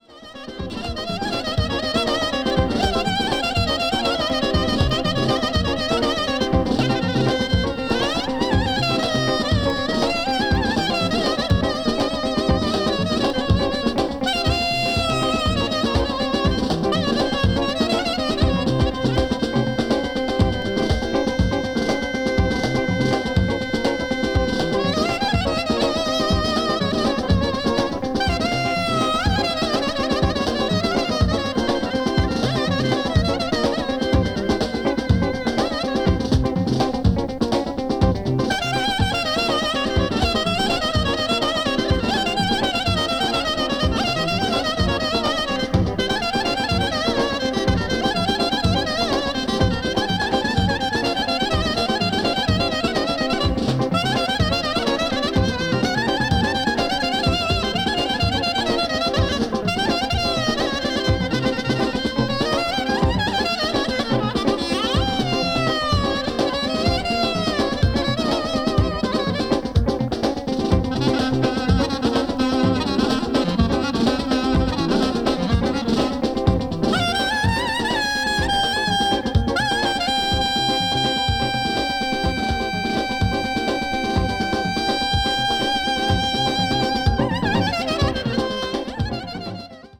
ethnic   folk   greece   psychedelic   world music